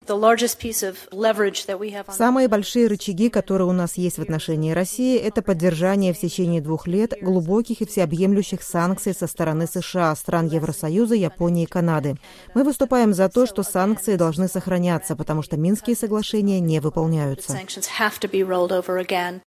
Помощник госсекретаря по делам Европы и Евразии Виктория Нуланд выступила на слушаниях в сенатском Комитете по иностранным делам